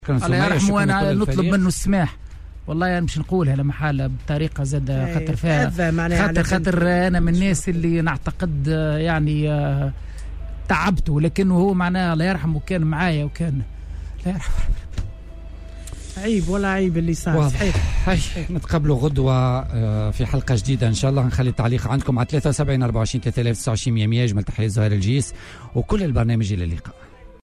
شوقي الطبيب يتأثّر حدّ البكاء